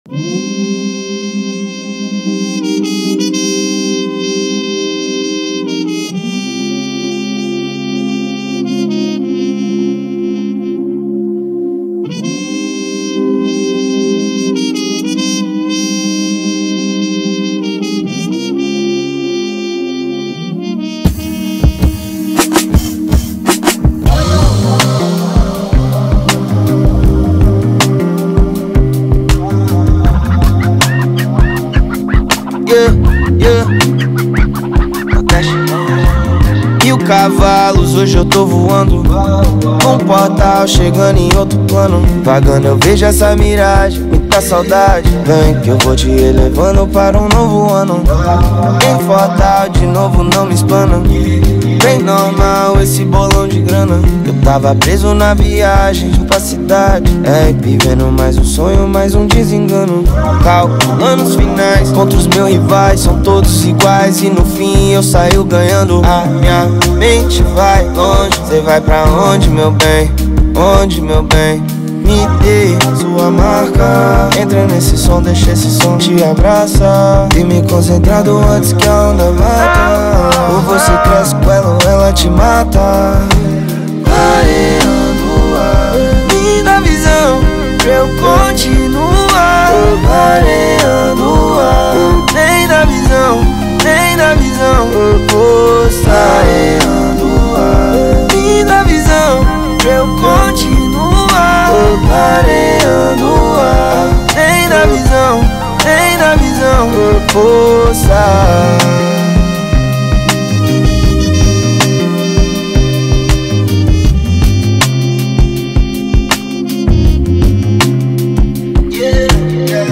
2024-10-13 11:01:45 Gênero: Trap Views